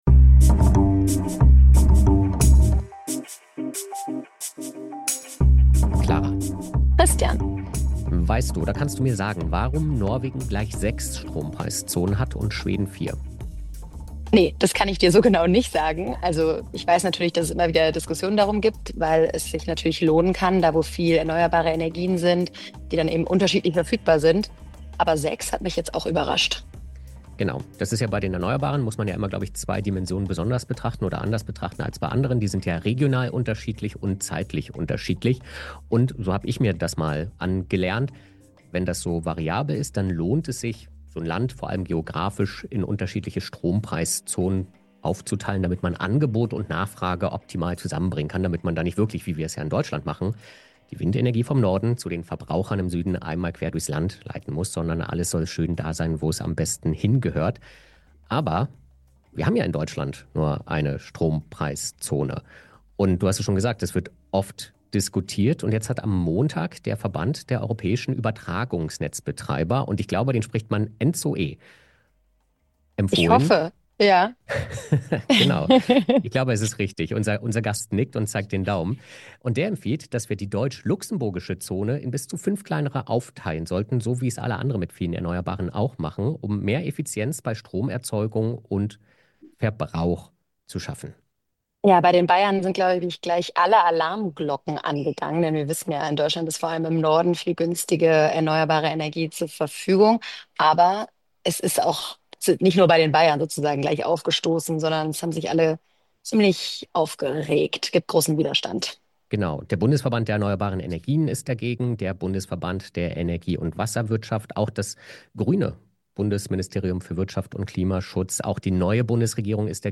Gast? Klaus Müller, Präsident der Bundesnetzagentur Moderation?
Dann bewertet das "Klima-Labor" bei Apple Podcasts oder Spotify Das Interview als Text?